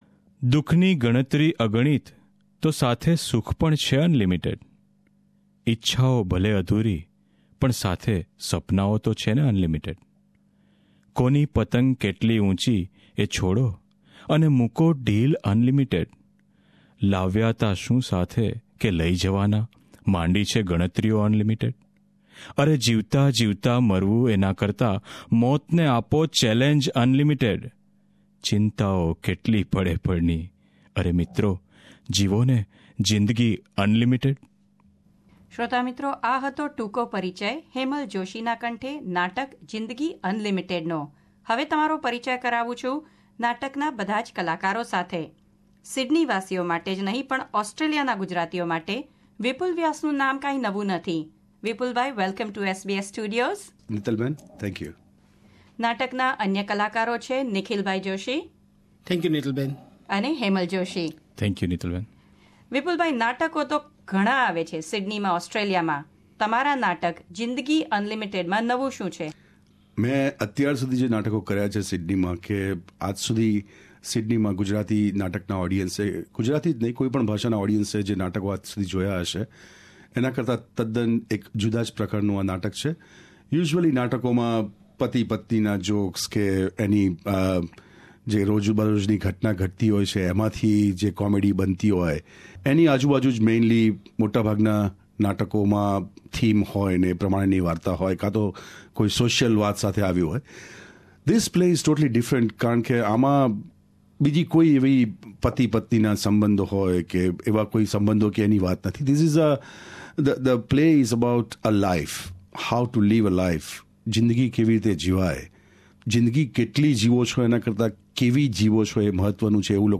વાર્તાલાપ. આવી રહેલ બીજા ભાગ માં જોઈશું નાટક પસંદ કરવાથી લઇ ને રંગમંચ પર ભજવવા સુધી ની પ્રક્રિયા કેવી હોય છે.